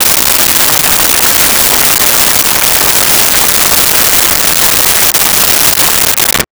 Board Room Applause
Board Room Applause.wav